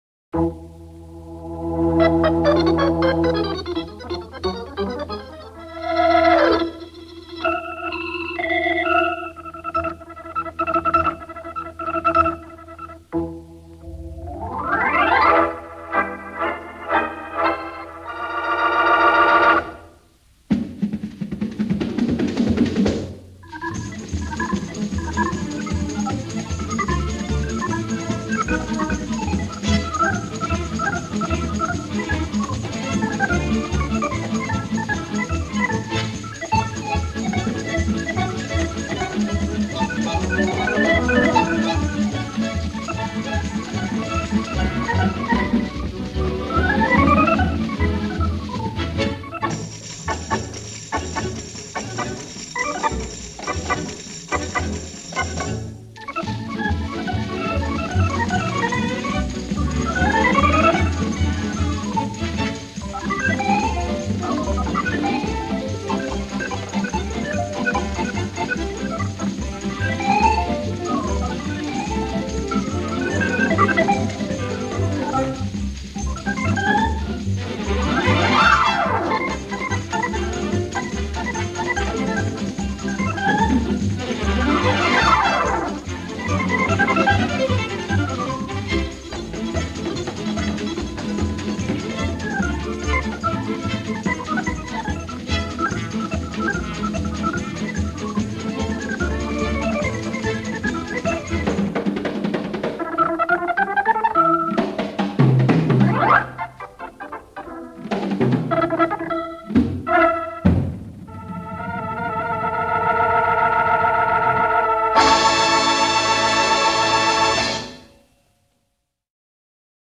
今天上传的这张唱片属于非常老的电子琴录音，录制于四十年代末期。我们可以领略到当年电子琴的声音是那麽飘渺，虚幻。
原声录音